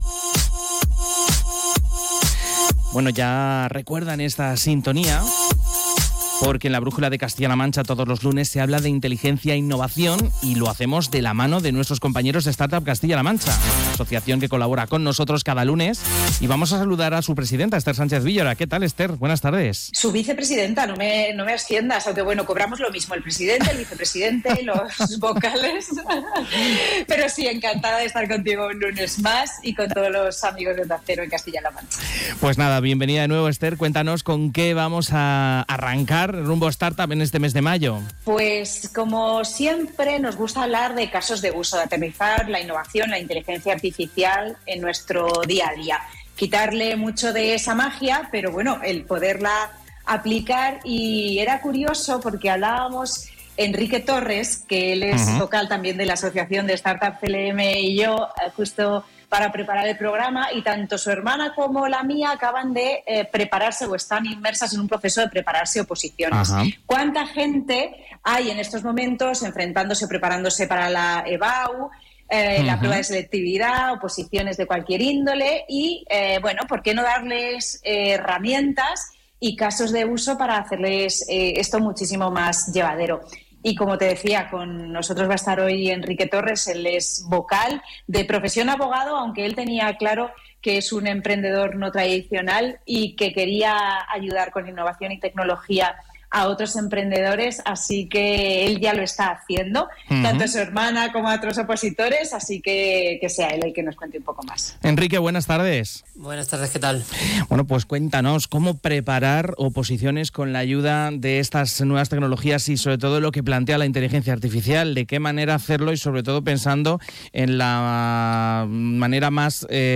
En la última edición de «Rumbo Startup» en «La Brújula de Castilla-La Mancha» de Onda Cero, se sentaron a charlar